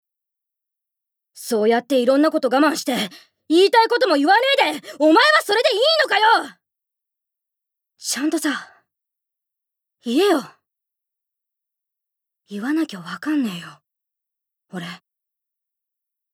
ボイスサンプル
セリフ２